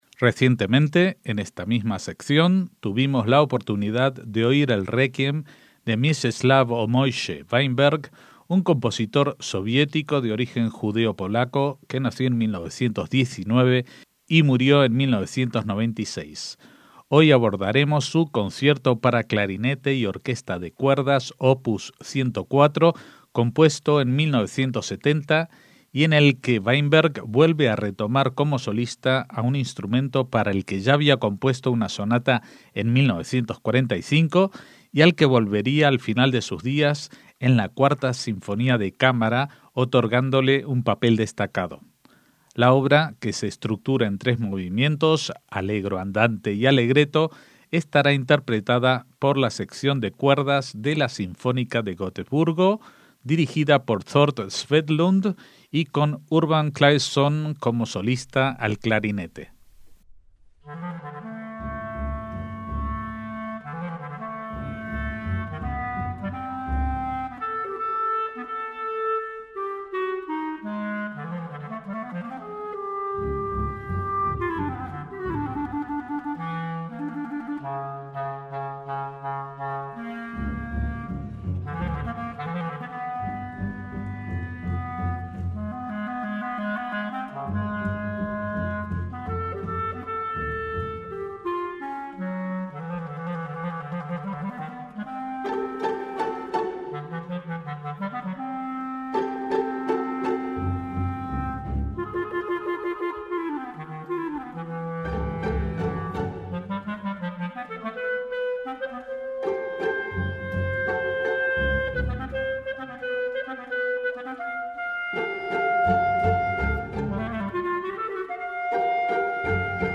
MÚSICA CLÁSICA